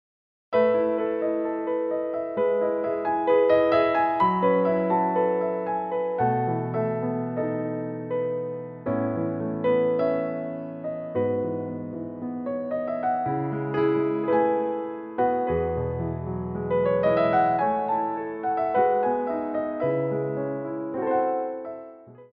Adagio
4/4 (8x8)